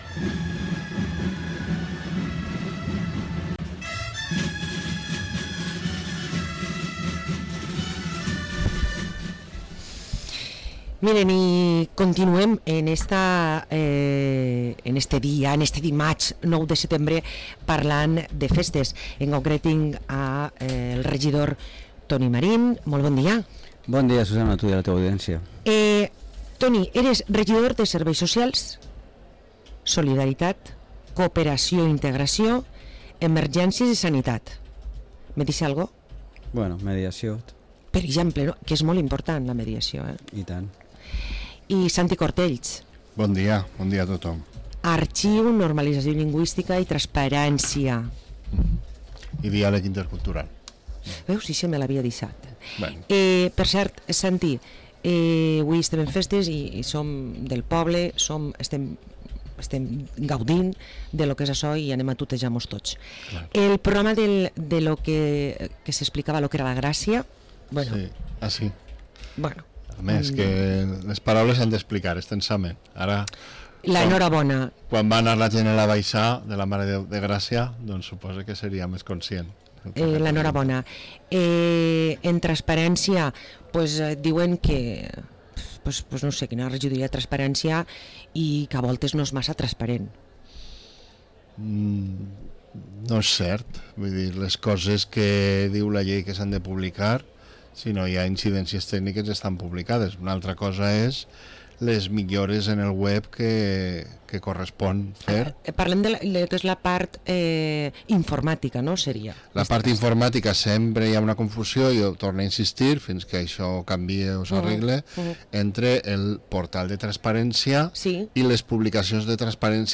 Parlem amb Toni Marín i Santi Cortells, regidors a l´Ajuntament de Vila-real